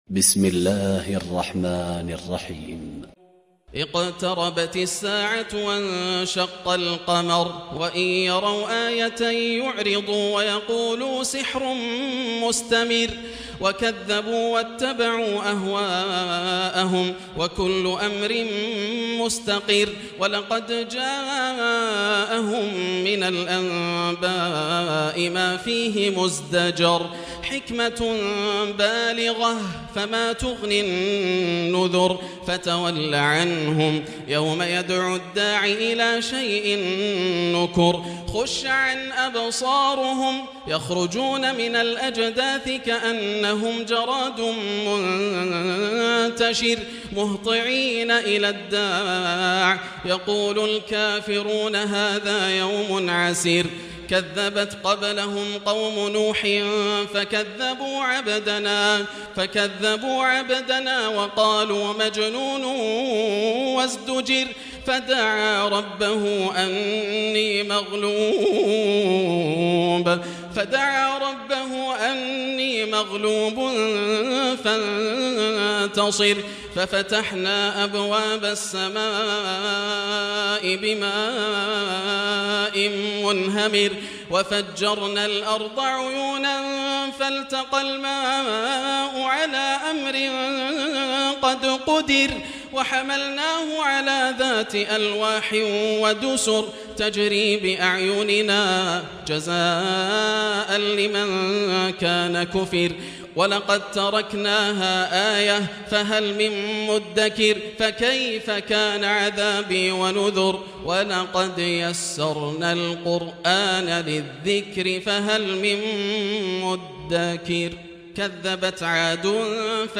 سورة القمر من تراويح رمضان 1440 هـ > السور المكتملة > رمضان 1440هـ > التراويح - تلاوات ياسر الدوسري